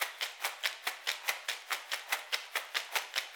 TYR SHAKER 2.wav